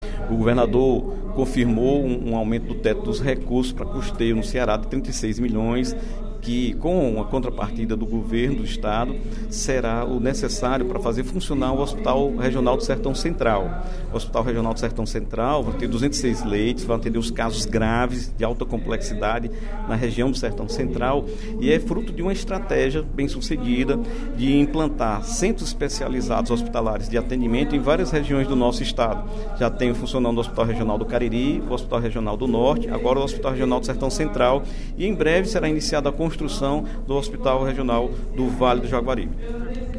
O deputado Dr. Santana (PT) comemorou, durante o primeiro expediente da sessão plenária da Assembleia Legislativa desta quarta-feira (04/05), a liberação de recursos para o funcionamento do Hospital Regional do Sertão Central, em Quixeramobim.